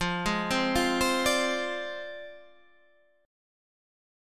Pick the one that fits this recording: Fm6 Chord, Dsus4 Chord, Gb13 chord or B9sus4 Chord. Fm6 Chord